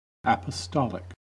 Ääntäminen
US : IPA : [ˌæ.pə.ˈstɑː.lɪk] RP : IPA : /ˌæpəˈstɒlɪk/